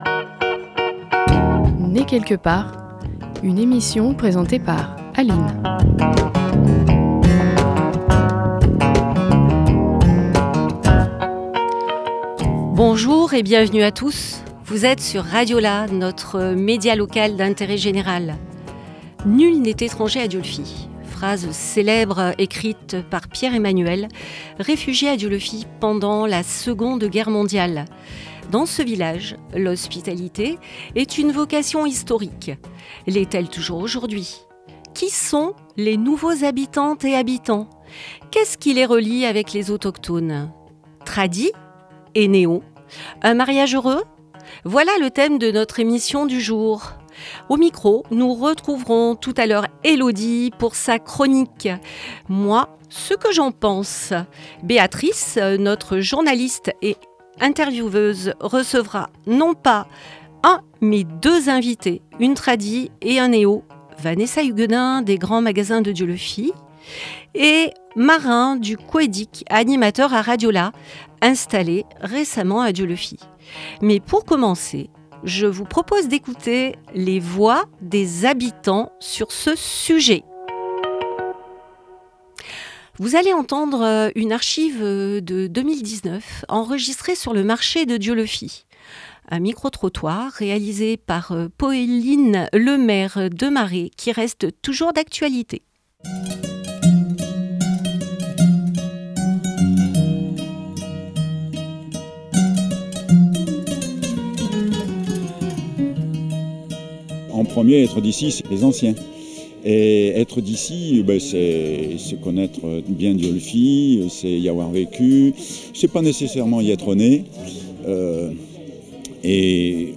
21 juillet 2025 14:32 | ateliers, Emissions Spéciales, Interview
Vous entendez également une chronique « Moi ce que j’en pense », et un micro-trottoir tiré des archives de RadioLà.